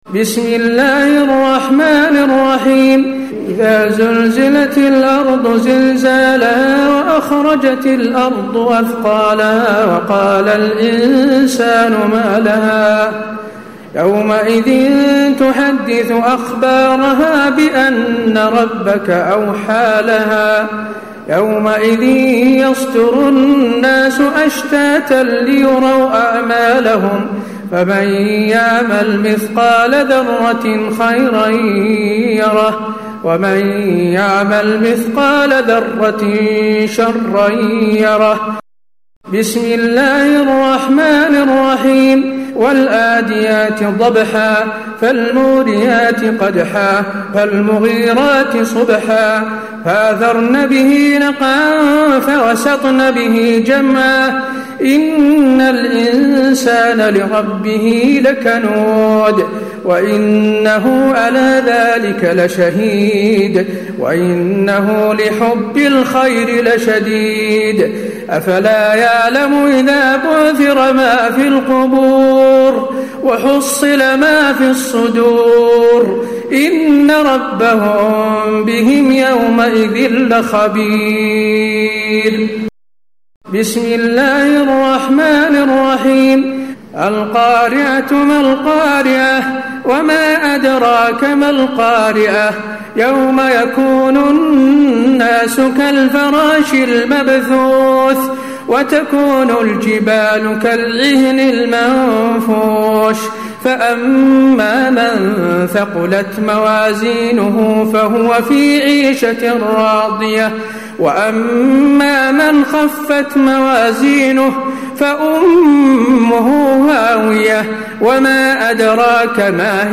تراويح ليلة 29 رمضان 1436هـ من سورة الزلزلة الى الناس Taraweeh 29 st night Ramadan 1436H from Surah Az-Zalzala to An-Naas > تراويح الحرم النبوي عام 1436 🕌 > التراويح - تلاوات الحرمين